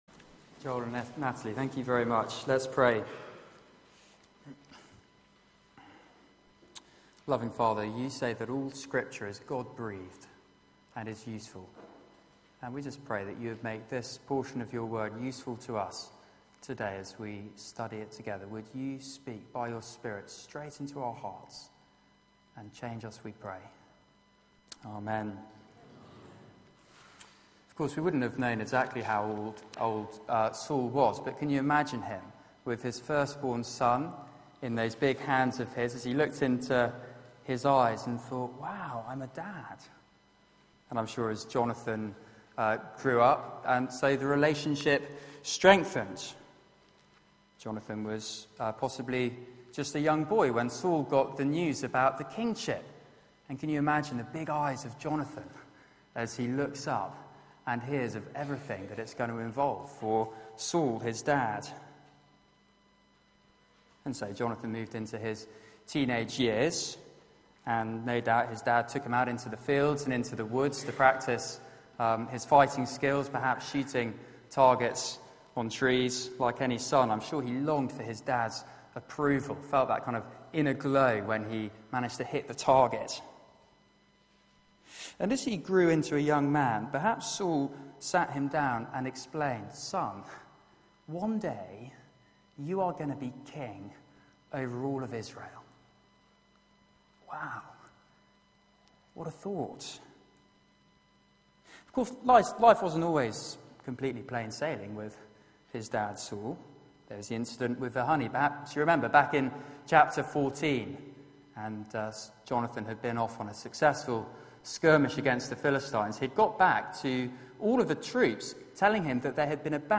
Media for 6:30pm Service on Sun 29th Jun 2014 18:30 Speaker
Theme: Saul, the Antichrist Sermon